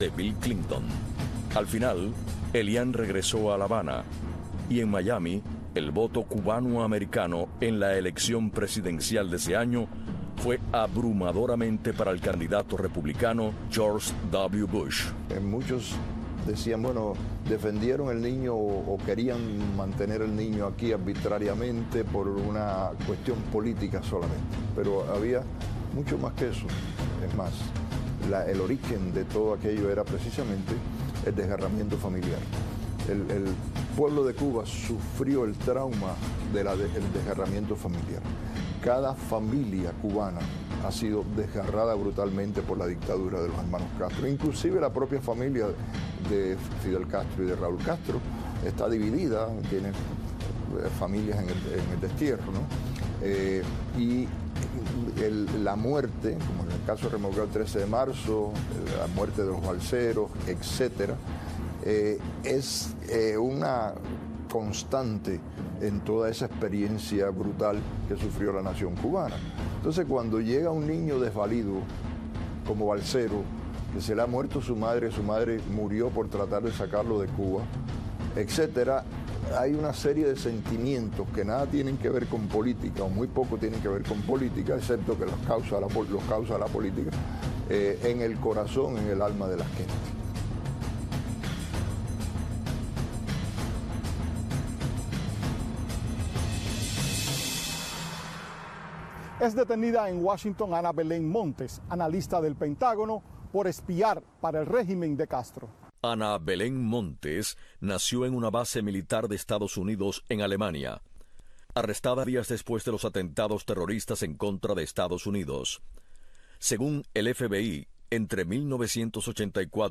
Presentador Tomas P. Regalado.